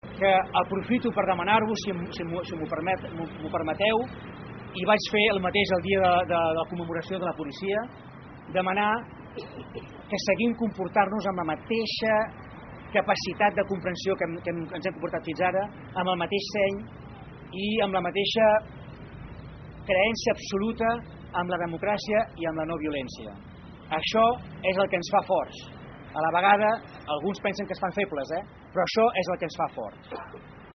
Davant de la imminència de saber la sentència l’alcalde de Palafolls, Francesc Alemany, va demanar ahir seny i confiança amb la democràcia i la no violència. Ho demanava ahir en l’acte d’inauguració del passatge en record del referèndum de l’1 d’octubre.